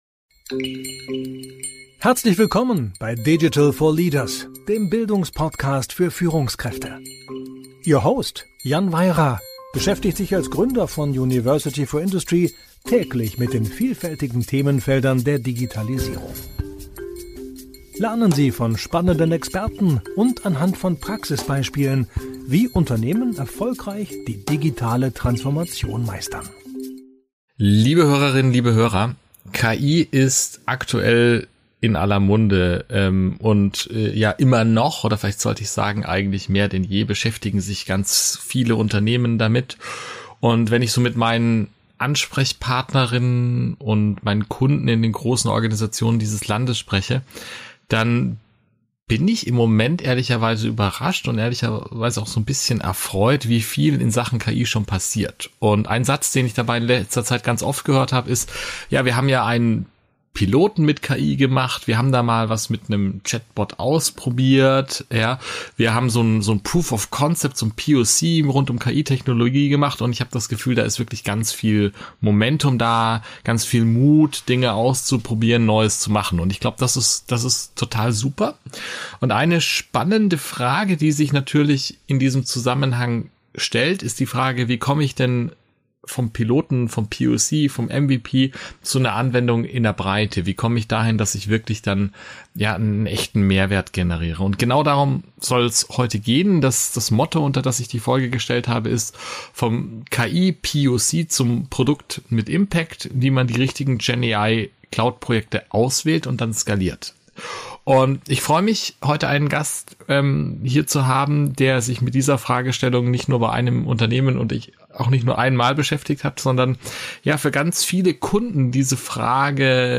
Gemeinsam tauchen sie tief in die Welt der Künstlichen Intelligenz (KI) und generativen KI ein und diskutieren deren Bedeutung und Potenziale.